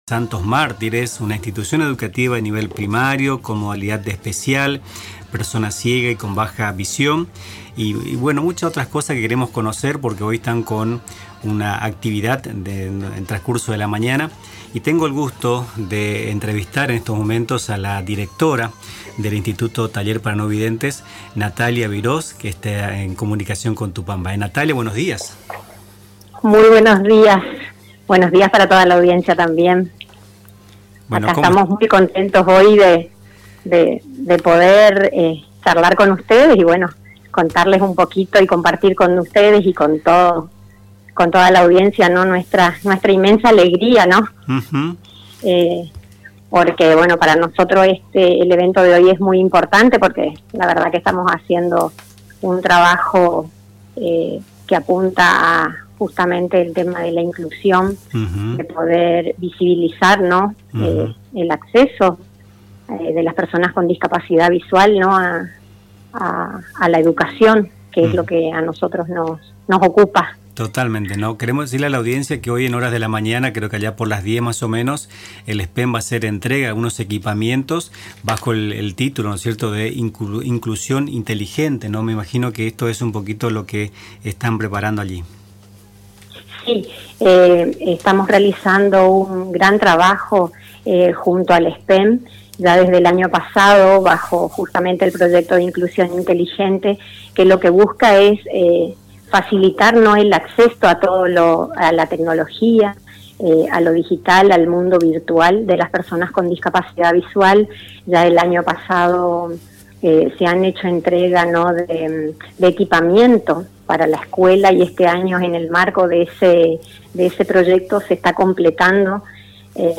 En diálogo con Nuestras Mañanas